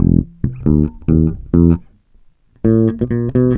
BASS LOOPS - PAGE 1 2 4 5